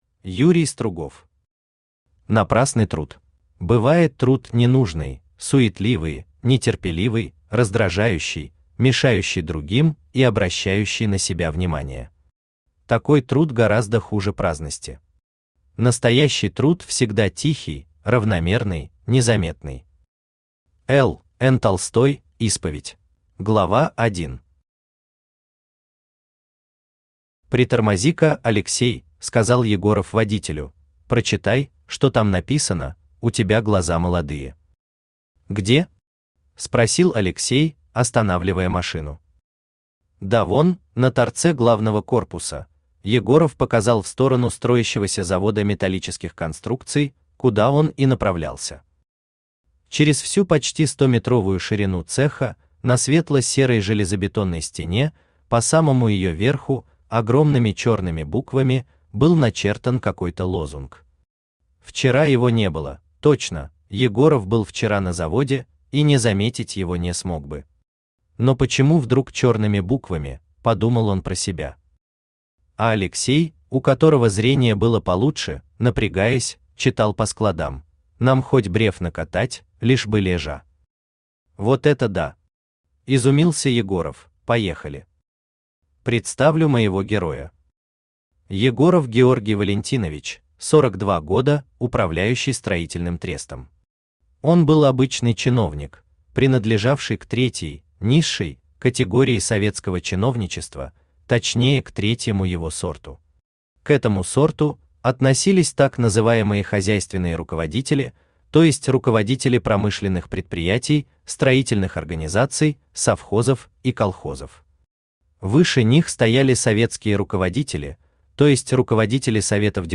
Аудиокнига Напрасный труд | Библиотека аудиокниг
Aудиокнига Напрасный труд Автор Юрий Валентинович Стругов Читает аудиокнигу Авточтец ЛитРес.